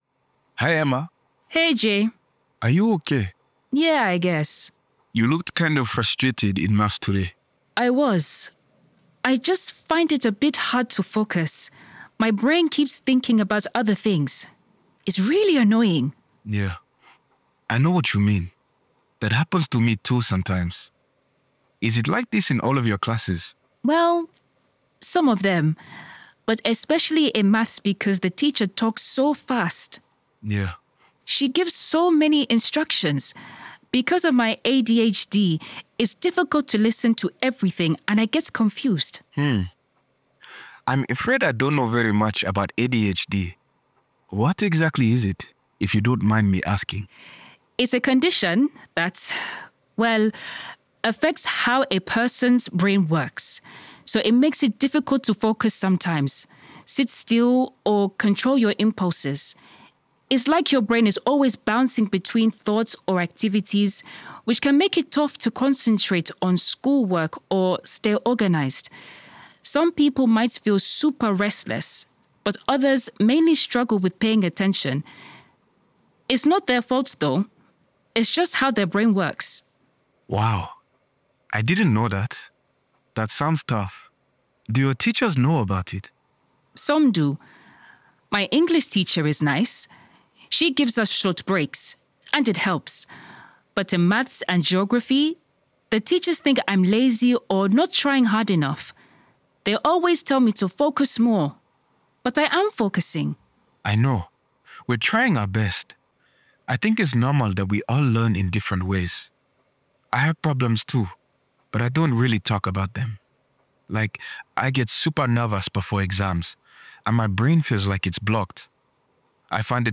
A2_B1_Dialogue_Challenges_at_school_v1.mp3